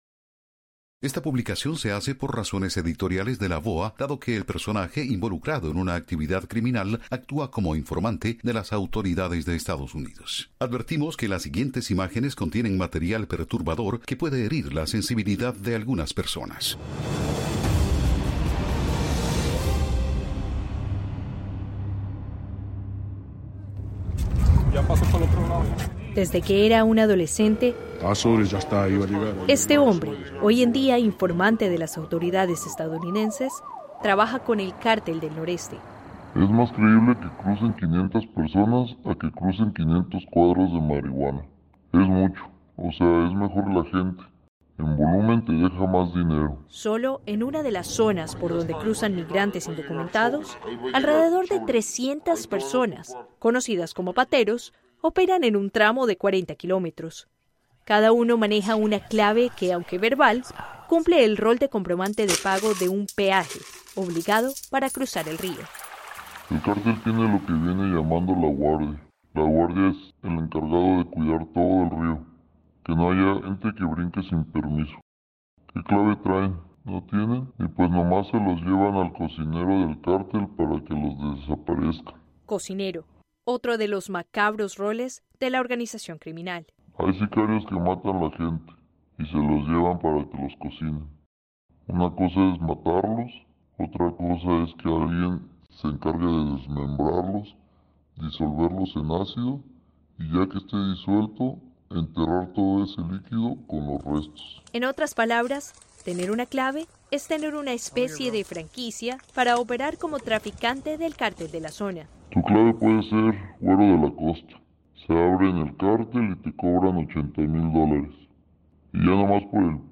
AudioNoticias
Concluimos este Especial que incluye una entrevista a un miembro de un cártel mexicano, sin revelar su identidad por razones editoriales de la VOA, actúa como informante de las autoridades de EEUU y revela el “modus operandi” del tráfico de migrantes.